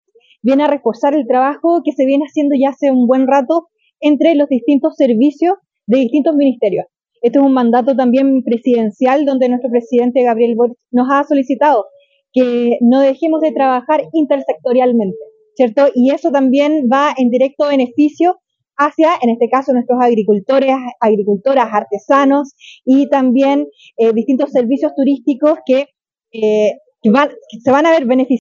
La actividad se realizó junto a productoras y cultoras de la región que dieron vida a una nueva versión del tradicional “Mercado Campesino” de Indap, en instalaciones del Parque Costanera de Puerto Montt; en el marco de la Semana de las Mipymes y Cooperativas 2024 que desarrolló la Seremía de Economía y sus servicios dependientes.
Quien también destacó la iniciativa fue la Seremi de Agricultura, Tania Salas indicando que esta alianza “viene a reforzar el trabajo que se viene haciendo hace rato, entre los diversos servicios y los ministerios.